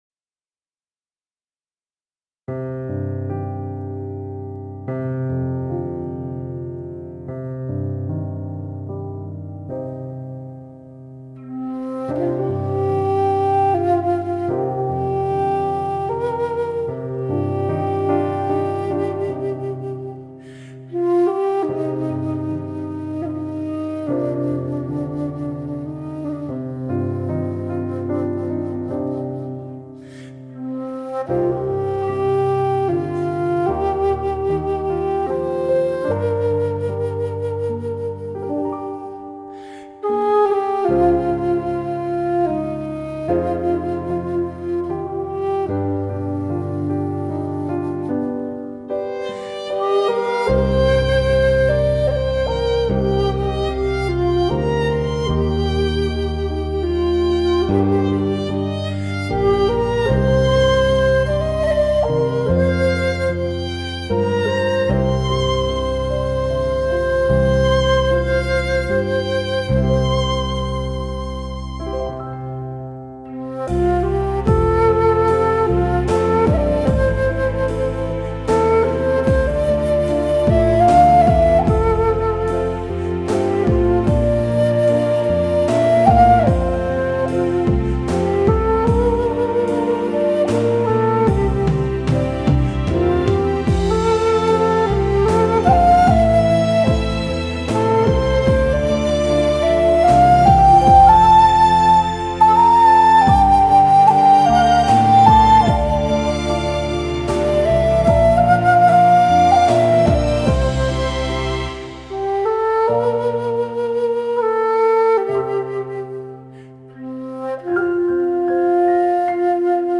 乐器：箫